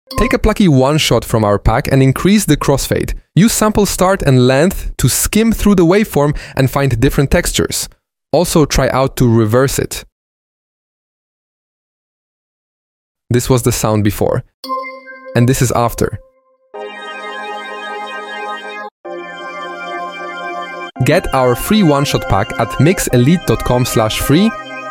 Use crossfade to smooth it out, then mess with sample start + length to grab different textures. Flip it in reverse for even crazier sounds. Before/after goes from basic to pro real quick.